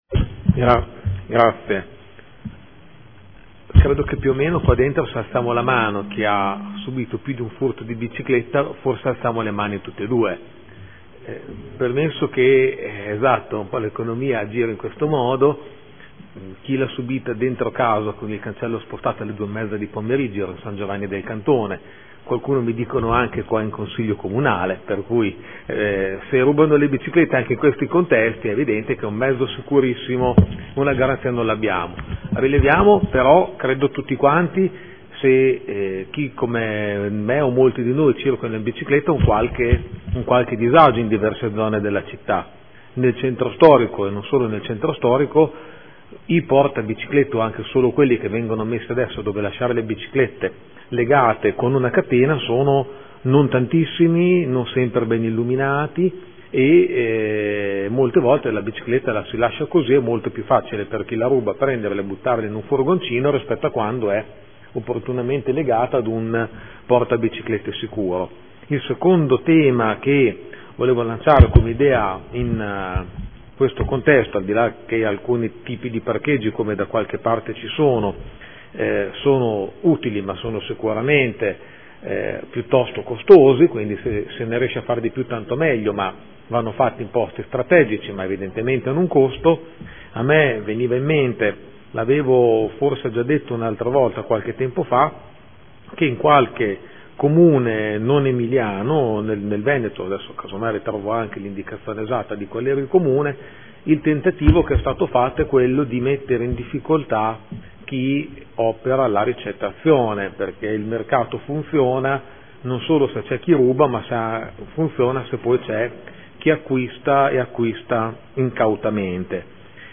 Dibattito